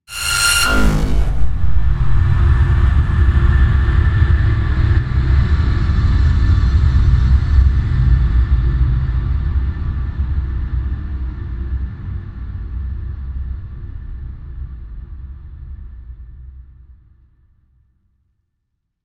HeartbeatStart.mp3